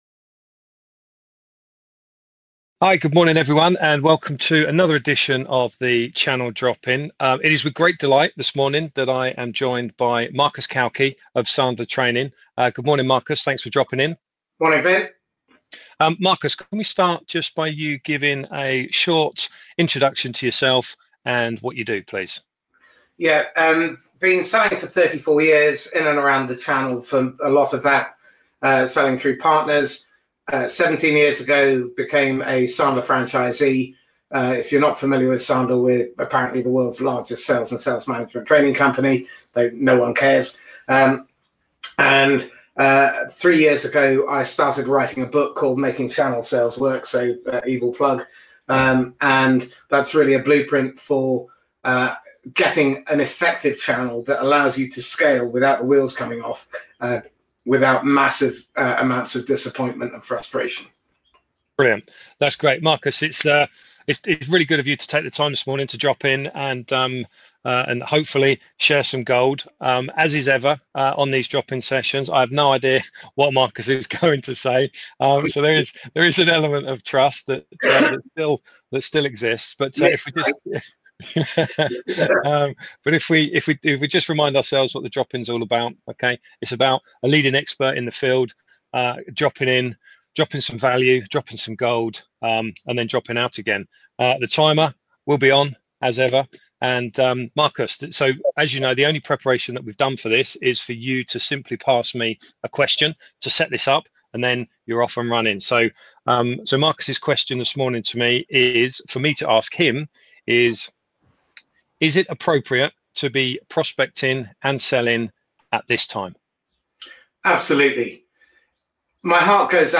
Hard hitting, being blunt and a few little swear words too, so don't be offended, you've had a warning.